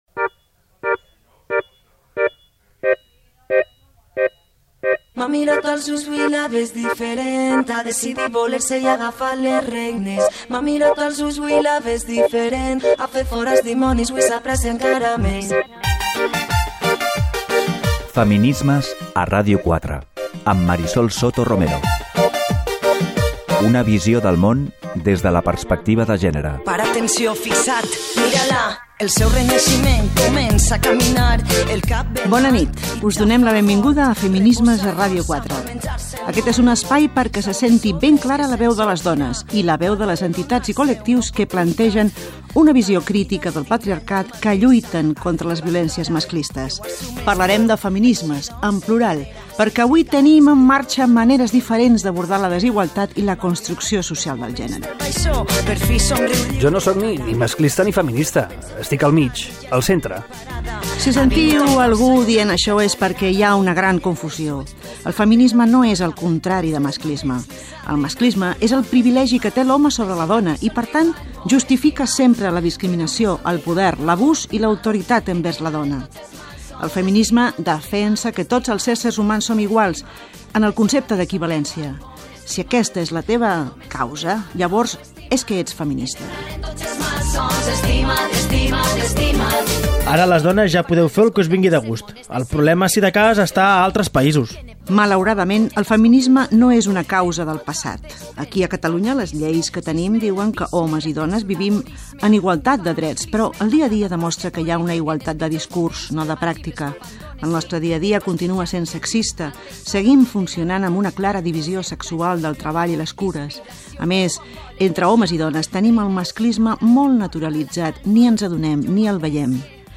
Careta del programa, presentació de la primera edició amb el seu objectiu i la definició de la causa feminista. Invitades del programa, indicatiu, la data del 8 de Març i la vaga convocada
Divulgació